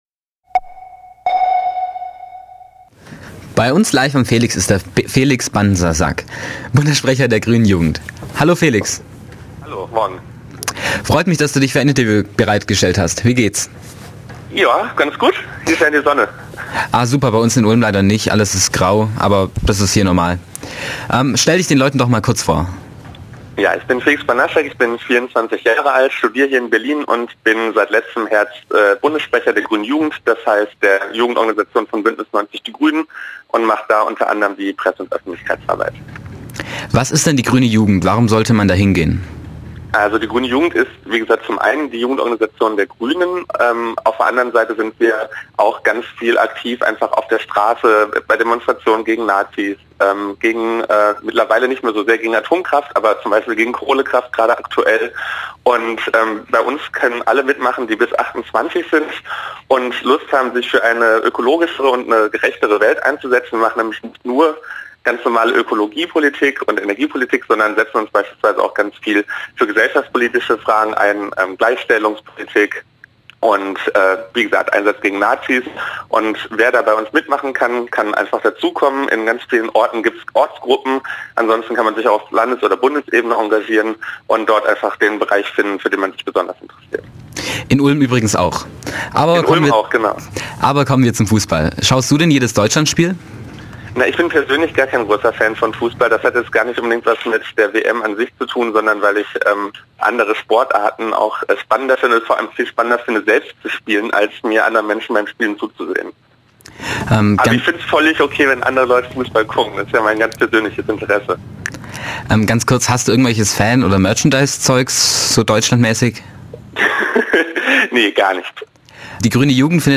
interview_felix_gruene_jugend.mp3